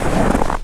STEPS Snow, Walk 01-dithered.wav